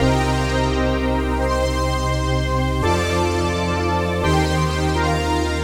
Index of /musicradar/80s-heat-samples/85bpm
AM_VictorPad_85-C.wav